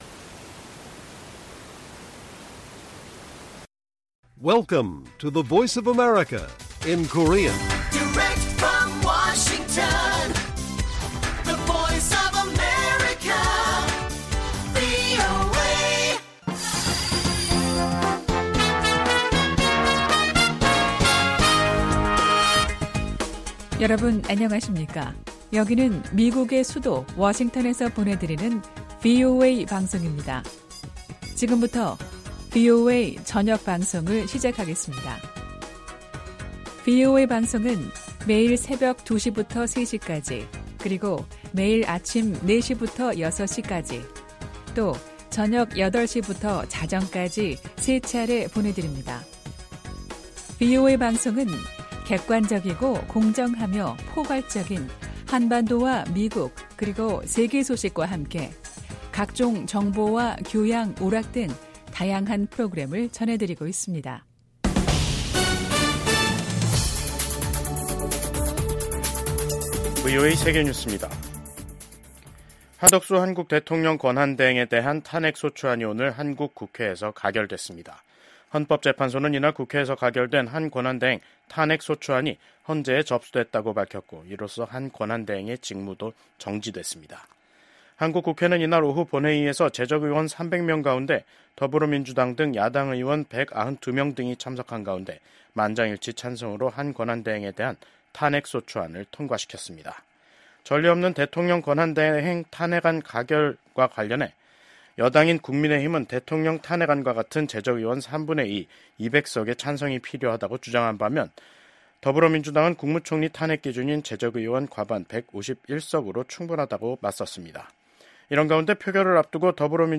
VOA 한국어 간판 뉴스 프로그램 '뉴스 투데이', 2024년 12월 27일 1부 방송입니다. 한덕수 한국 대통령 권한대행 국무총리의 탄핵 소추안이 국회에서 가결됐습니다. 한국 국가정보원은 북한 군 1명이 러시아 쿠르스크 전장에서 생포됐다는 우크라이나 매체 보도에 대해 사실이라고 밝혔습니다. 미국 전문가들은 역내 안보환경을 고려할 때 미한일 협력의 필요성이 여전히 높다고 평가했습니다.